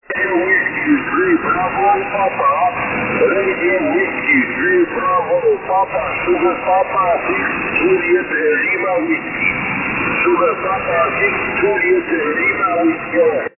Ниже приведены фрагменты записи моих связей в формате mp3. Длительность своей передачи урезал с помощью редактора.